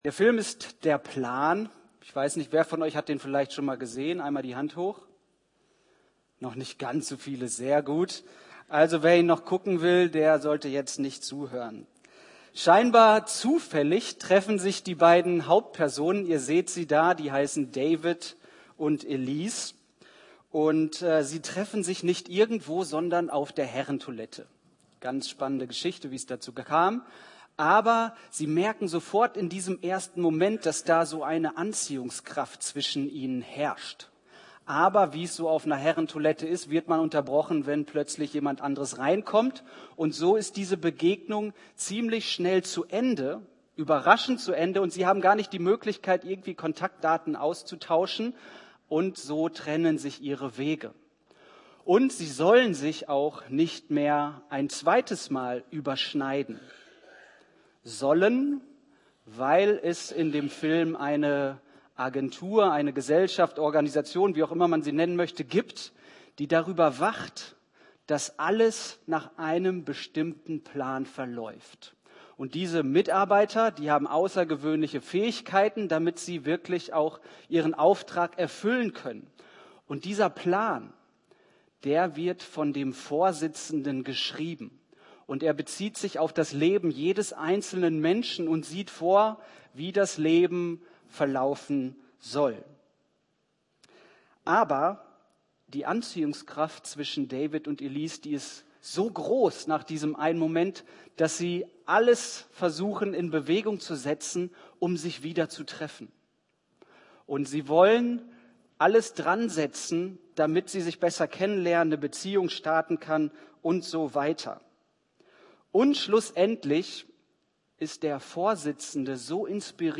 Predigt Sein Plan ist größer